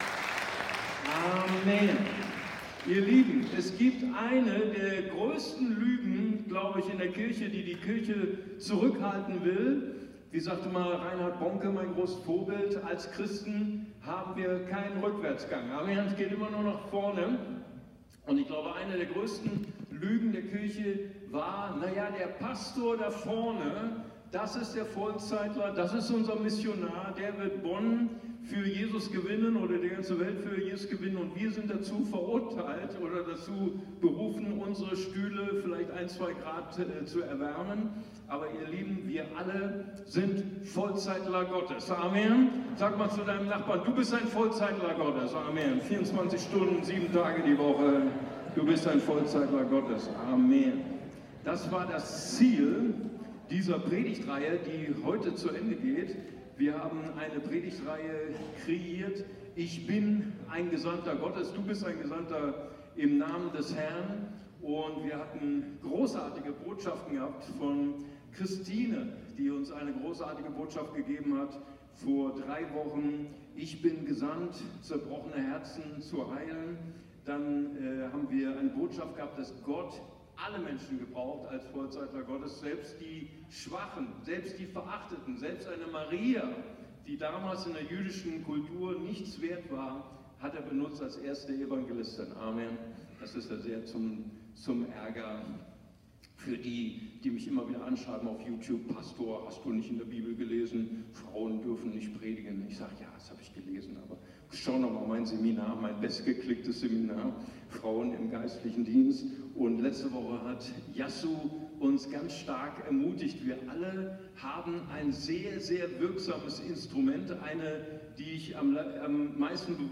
Predigtreihe über die „Aussendungsrede Jesu“ Matthäus Evangelium 10,5-42; 11,1